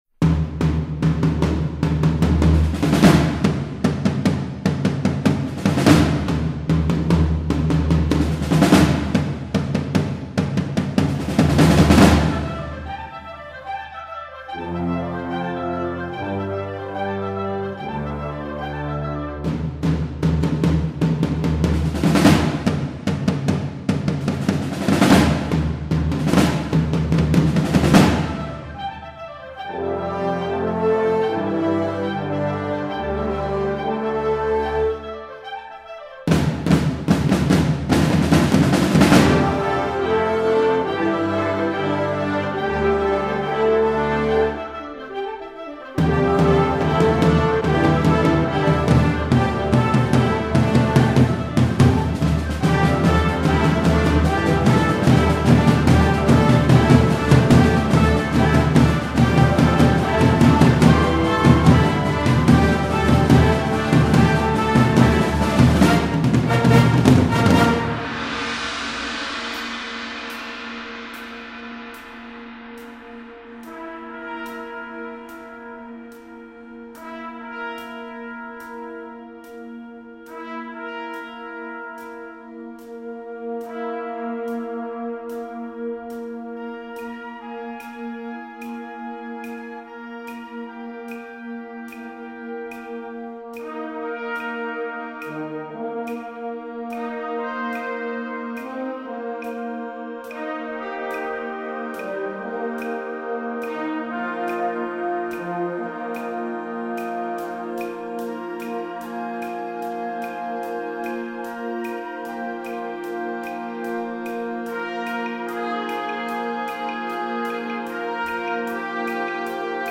Genre: Band
It's game time!
Piccolo
Tuba
Percussion 1 (2 toms, hi hat, glockenspiel)
Percussion 2 (2 toms, tam tam, claves, vibraphone)
Percussion 3 (snare drum, chimes)